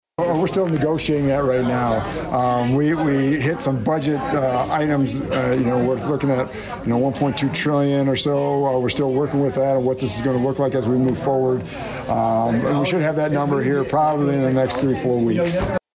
Humboldt, IA – Iowa Congressman Randy Feenstra held a town hall at KC Nielsen midday Wednesday to talk about the 2023 Farm Bill among other issues.